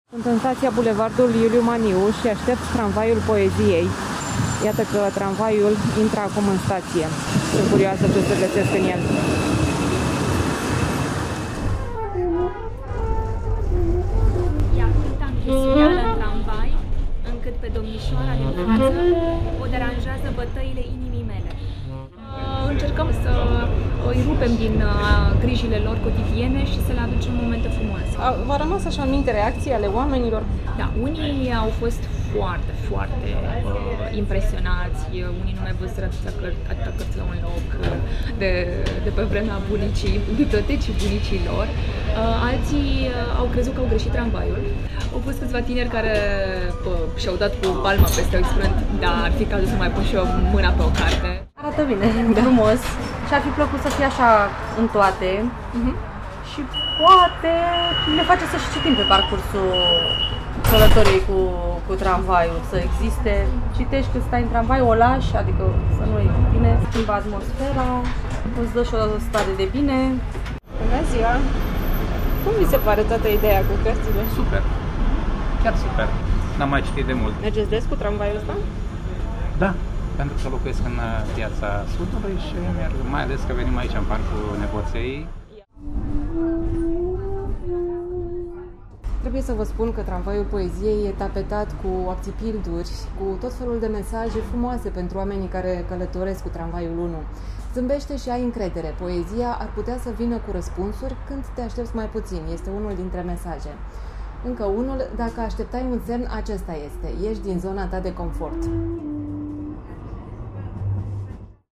Vineri dimineața, stația de tramvai de pe bulevardul Iuliu Maniu, din zona Apaca – Tramvaiul Poeziei intră în stație.